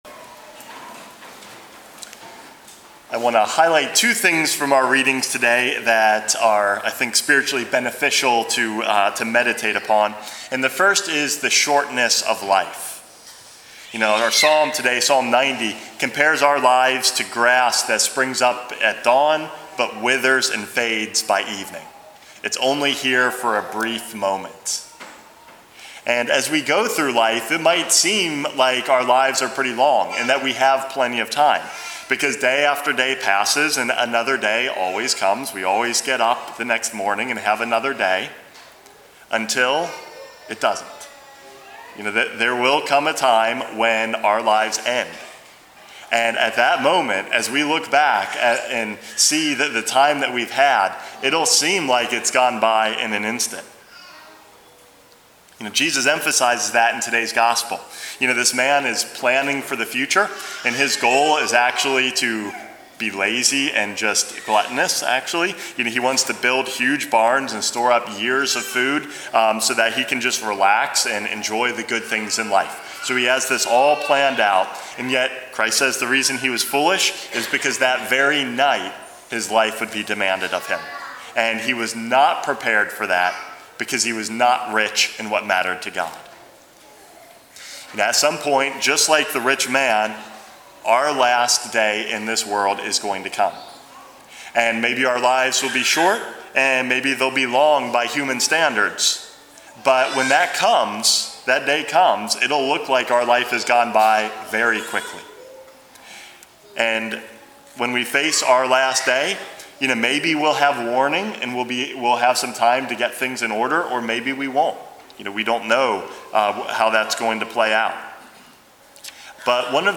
Homily #458 - The Value of Time